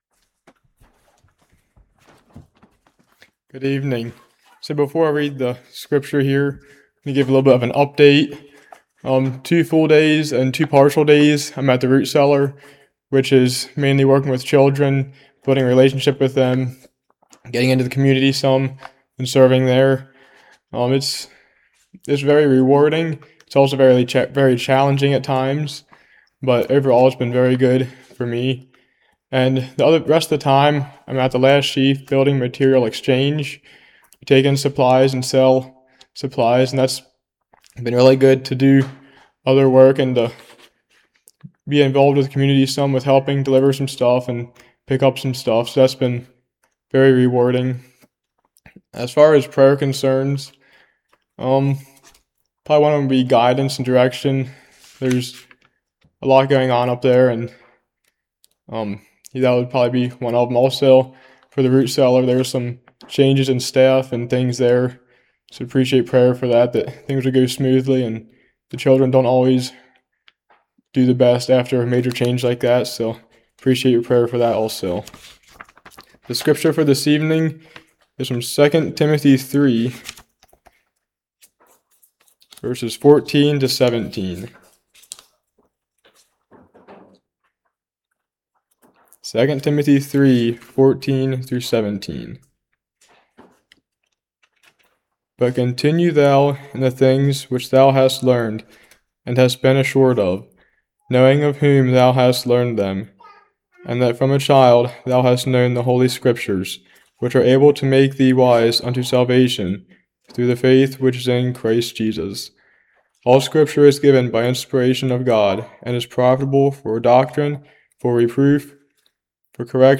2 Timothy 3:14-17 Service Type: Evening 2 Tim 3:14-17 All Scripture God Breathed. 2 Pet 1:19-21 No private interpretation.